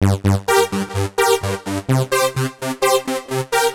Das Groove G 128.wav